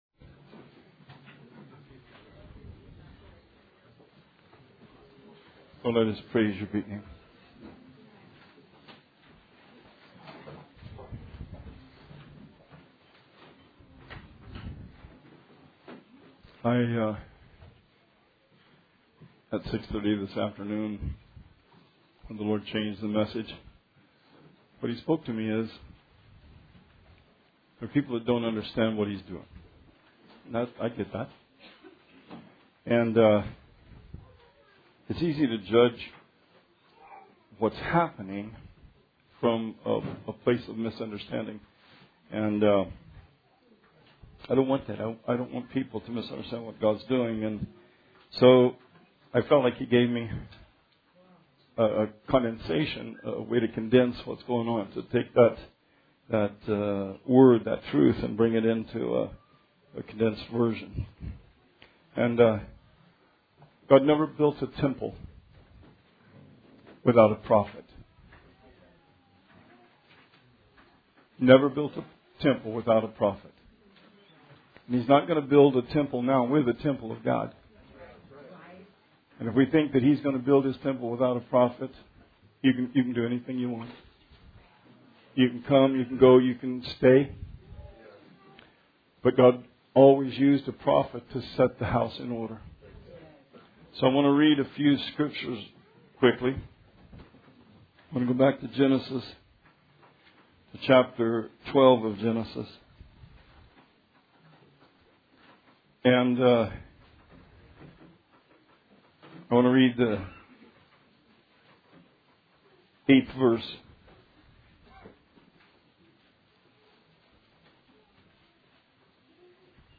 Sermon 12/31/19